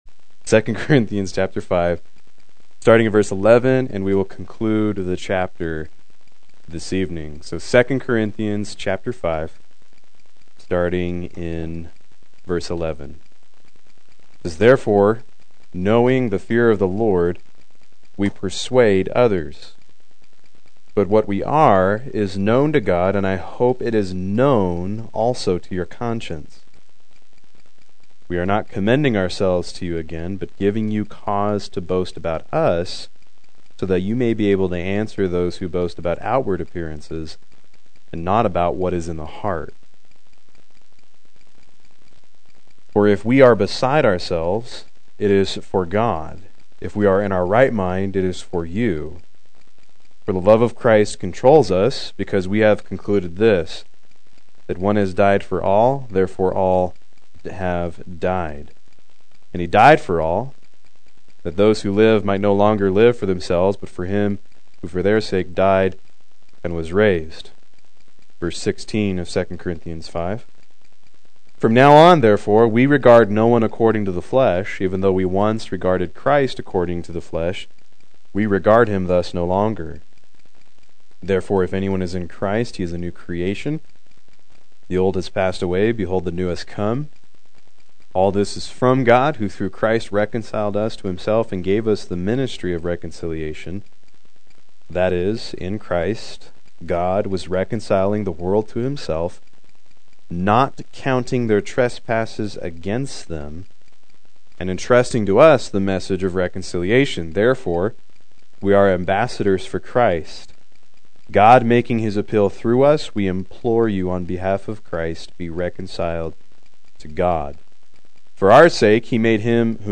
Proclaim Youth Ministry - 04/14/17
Play Sermon Get HCF Teaching Automatically.